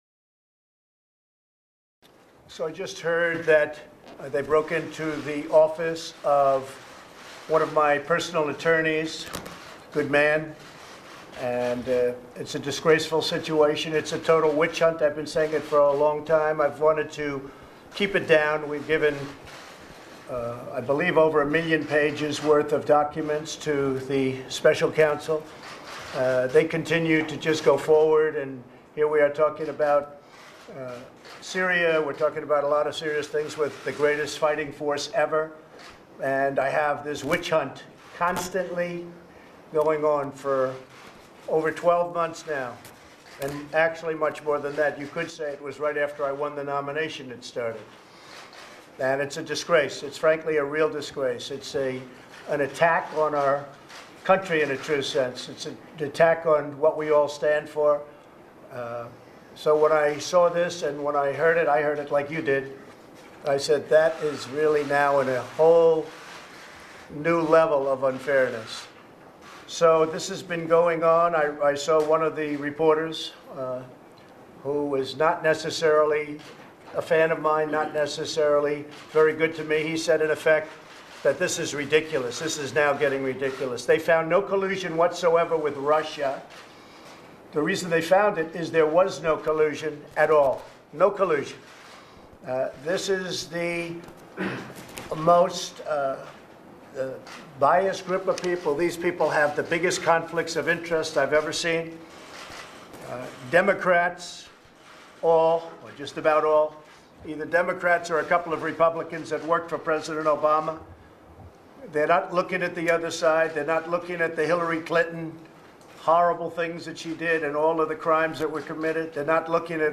President Donald Trump comments on the news of an F.B.I. raid at the office of Trump's personal attorney Michael Cohen. Trump asserts that Cohen is a good man and the raid is a part of the ongoing "witch-hunt" against him.